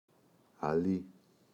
αλί [aꞋʎi]